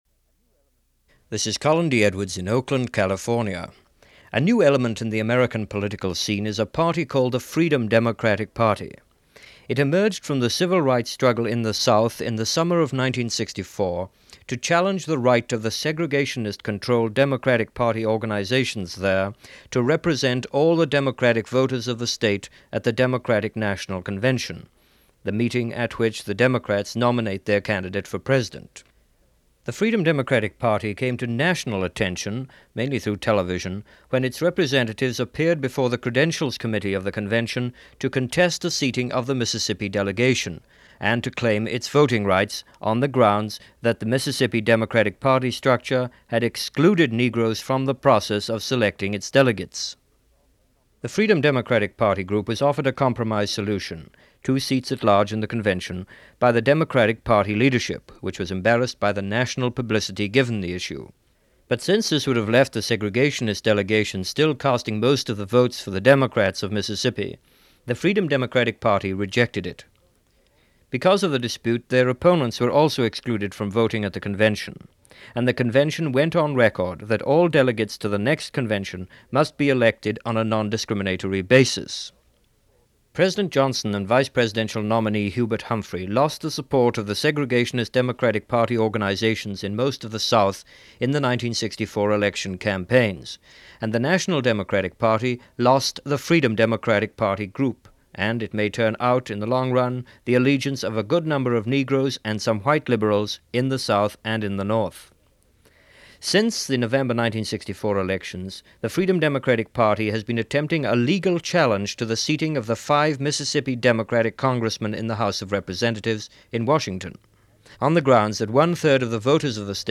A Word or Two From Fannie Lou Hamer - 1965 - interview with the civil rights activist - 1965 - Past Daily Reference Room.